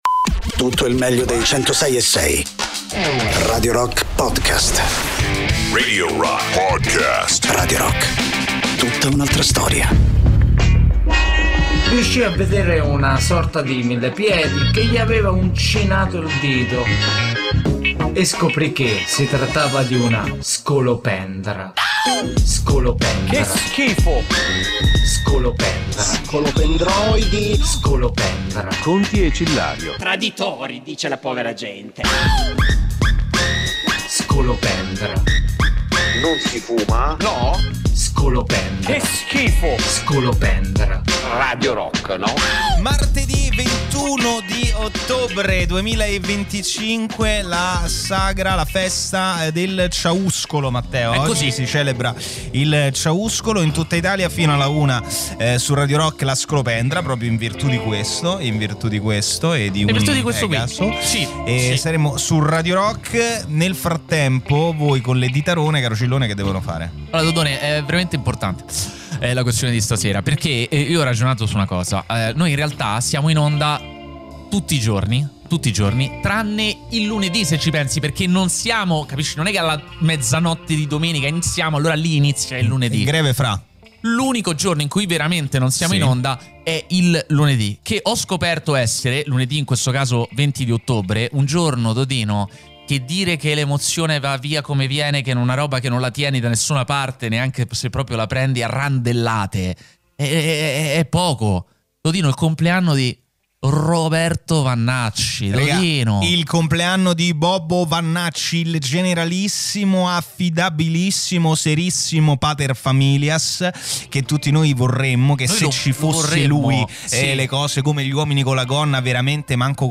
in diretta Sabato e Domenica dalle 15 alle 18